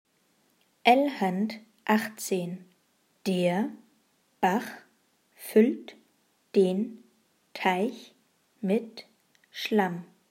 Satz 17 Schnell
L-17-schnell.mp3